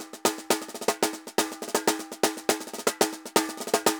Tambor_Salsa 120_1.wav